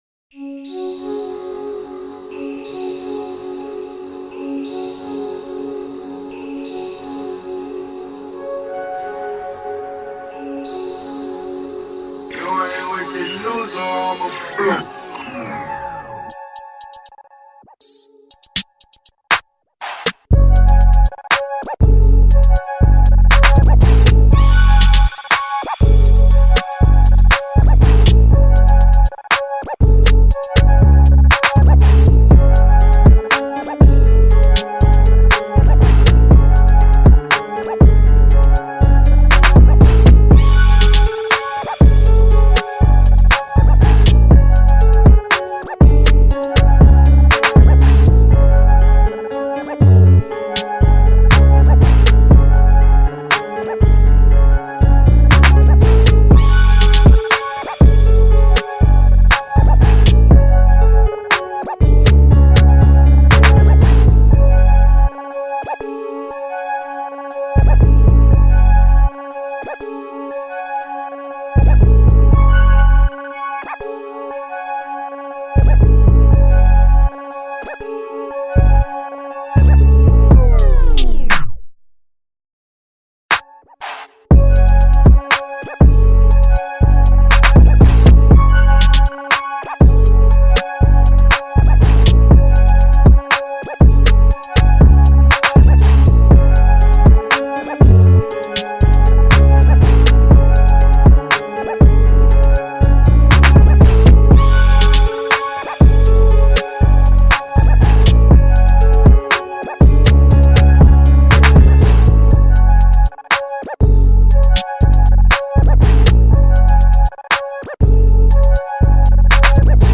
official instrumental
Rap Instrumental